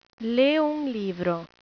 In this page, you can hear some brazilian portuguese words/phrases.